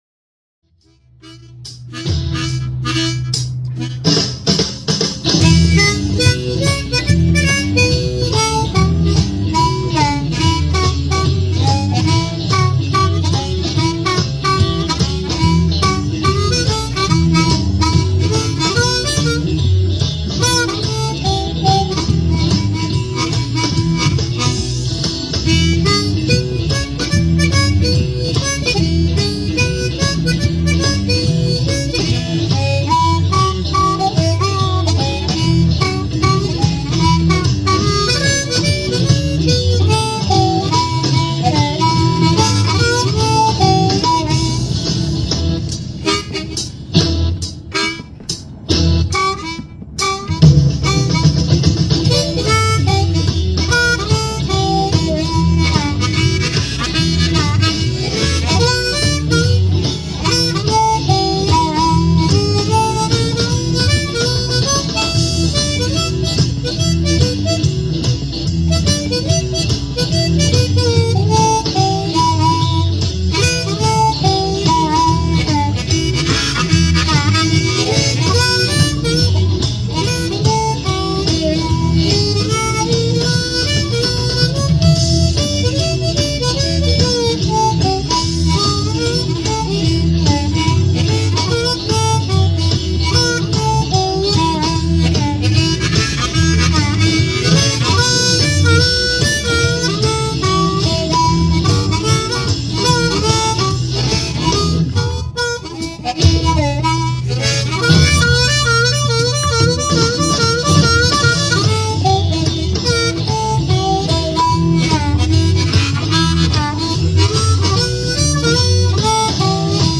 Mundharmonika MP3 Hörbeispiele Download
Got the Blues.....